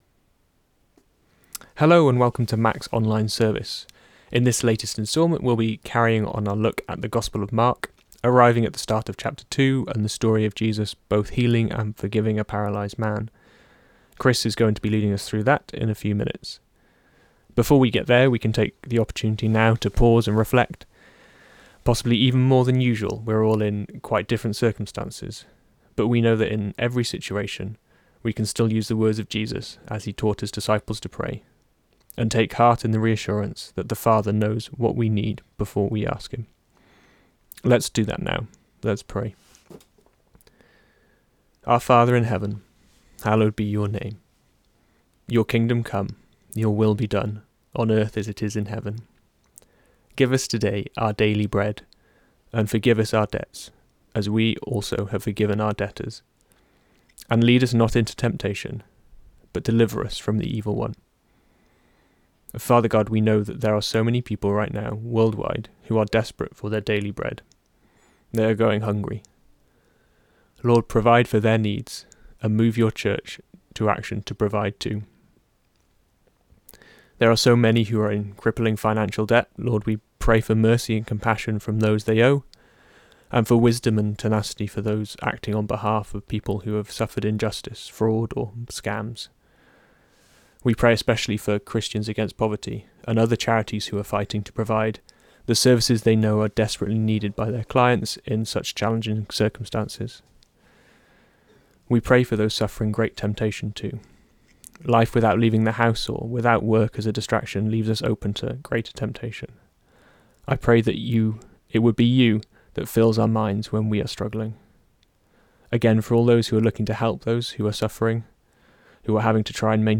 Service from Mack Church with prayer, songs and a talk on Mark 2 v 1-12
Service Type: Sunday Morning